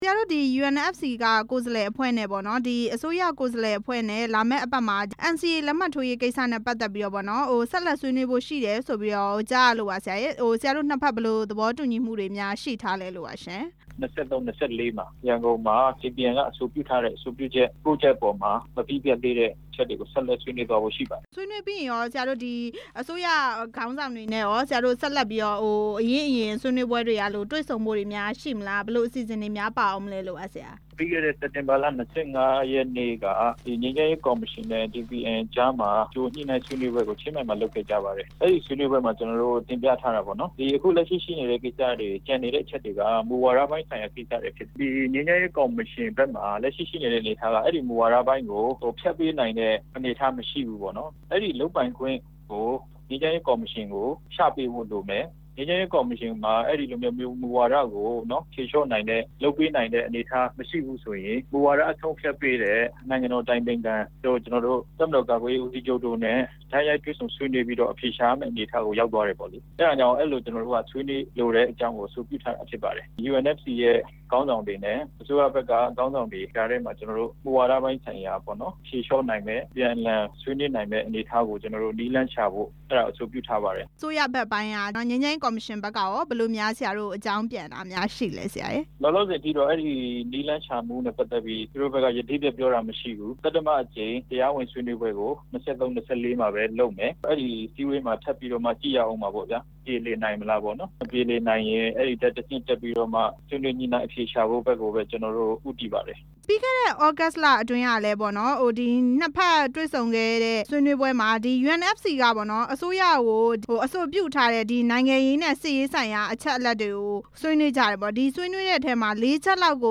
UNFC နဲ့ အစိုးရအဖွဲ့ တွေ့ဆုံမယ့်အကြောင်း မေးမြန်းချက်